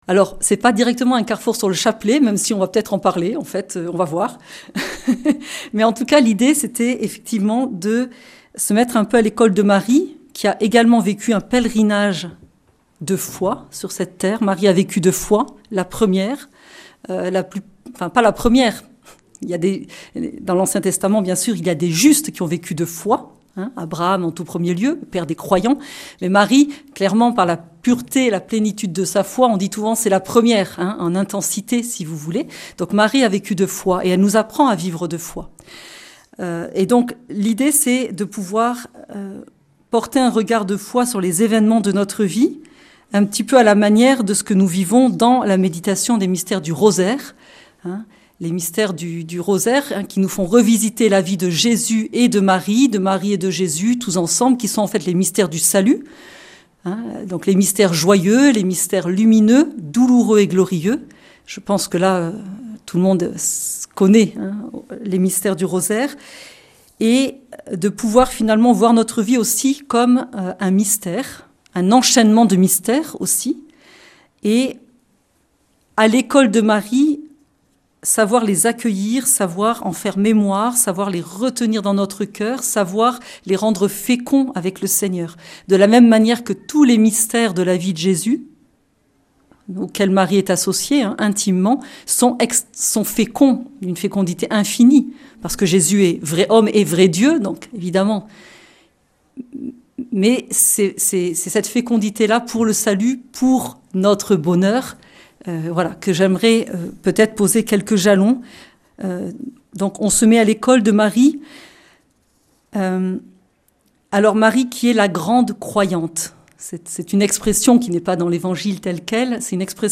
Lourdes - Session des béatitudes 2024